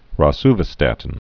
(rŏ-svə-stătn)